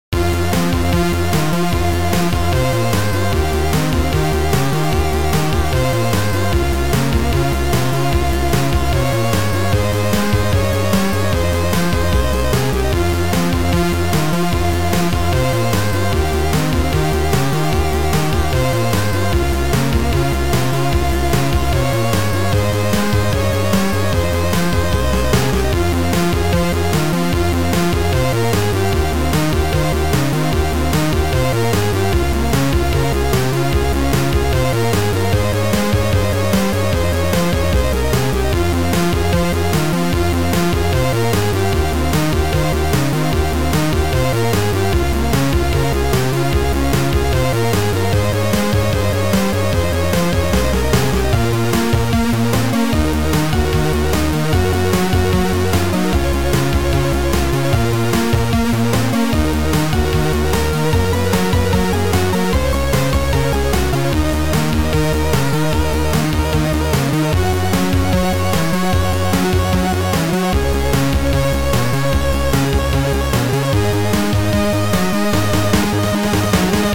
Sound Format: Future Composer 1.4